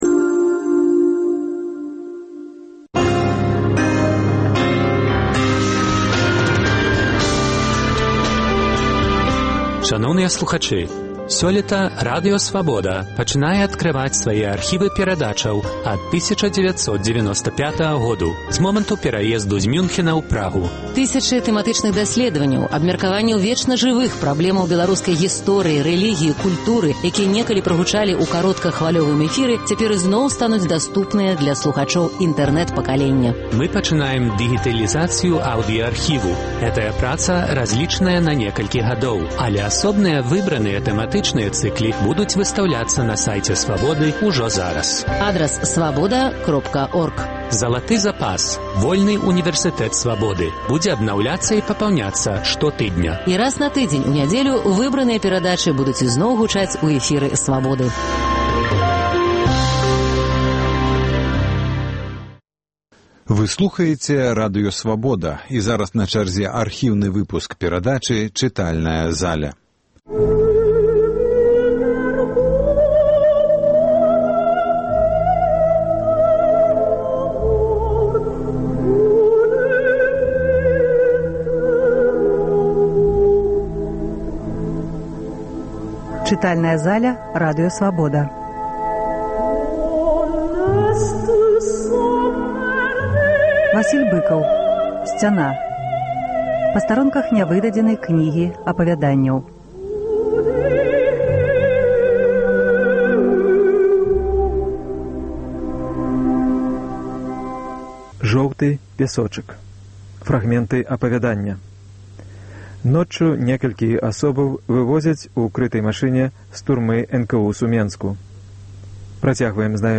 Аўдыёархіў найлепшых перадачаў Свабоды. Гэтым разам Васіль Быкаў разам з супрацоўнікамі Свабоды чытае ўрыўкі сваіх твораў з кнігі "Сьцяна".